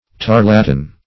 Tarlatan \Tar"la*tan\, n. A kind of thin, transparent muslin, used for dresses.
tarlatan.mp3